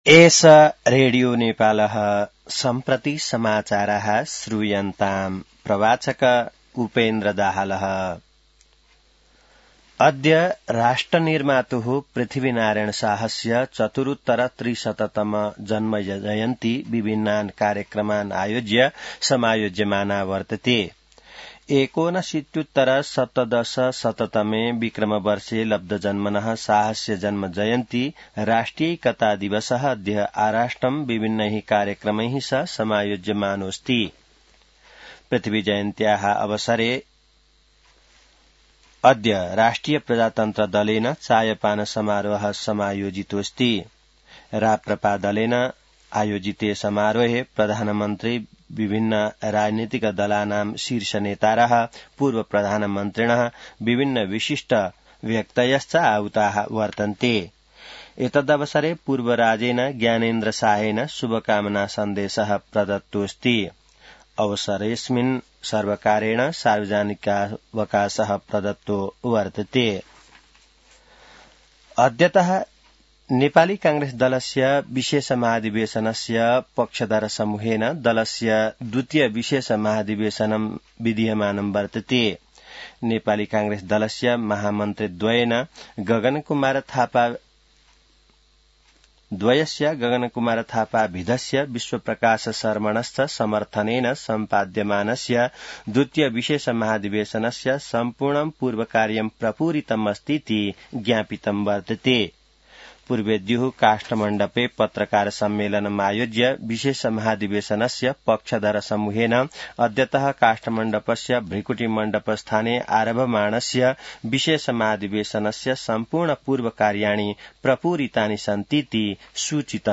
संस्कृत समाचार : २७ पुष , २०८२